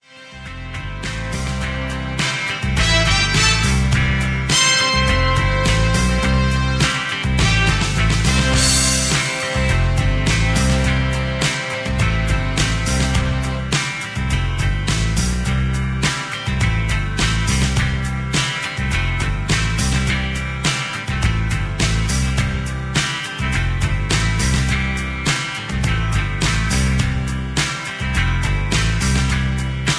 mp3 backing tracks